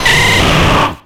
Cri de Florizarre dans Pokémon X et Y.